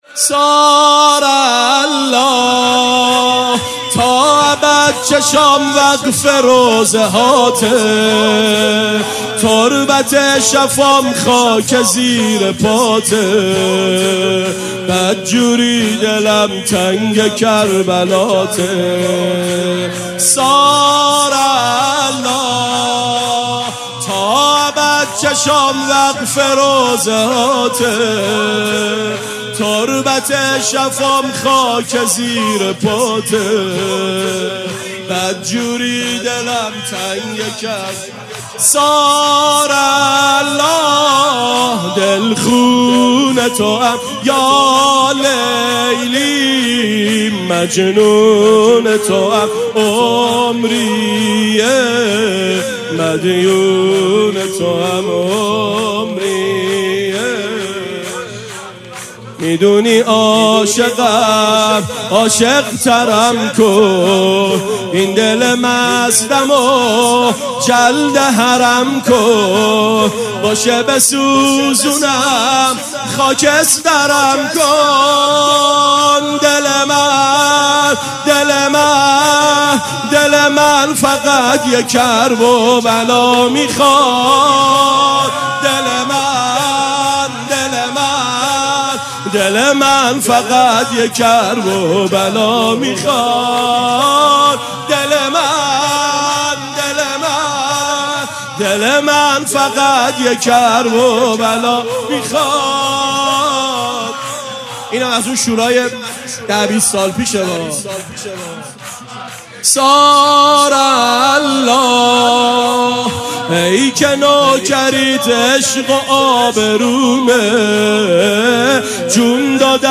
شور ثارالله
شب ششم محرم ۱۴۴۴/ ۱۲ مرداد ۴۰۱